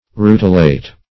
Search Result for " rutilate" : The Collaborative International Dictionary of English v.0.48: Rutilate \Ru"ti*late\, v. i. [L. rutilare, rutilatum.] To shine; to emit rays of light.